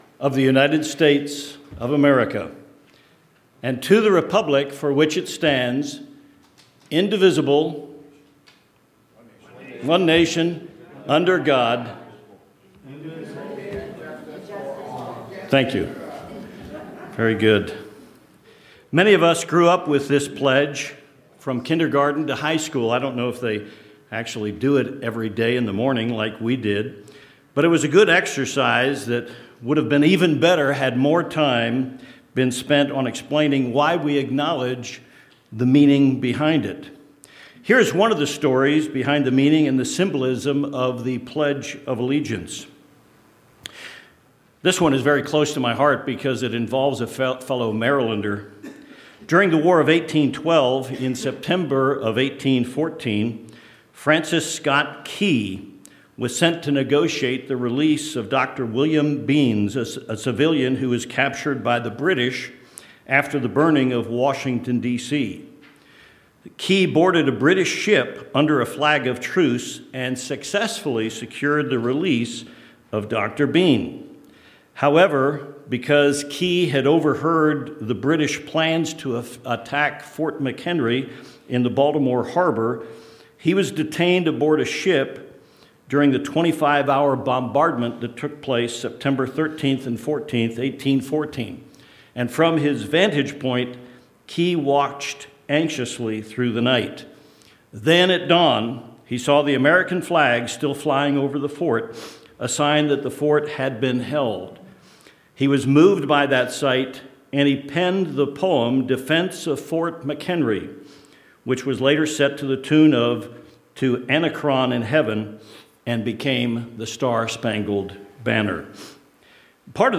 Given in Tucson, AZ El Paso, TX